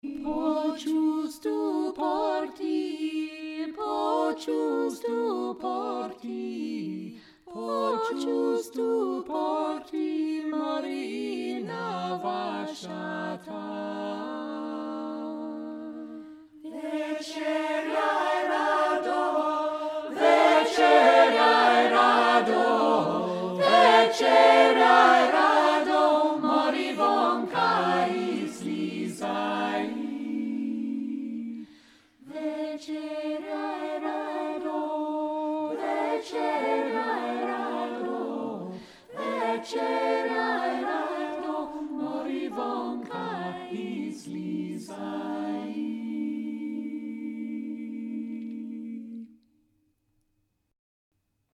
calm and riotous, exultant and tranquil
Trad. Bulgarian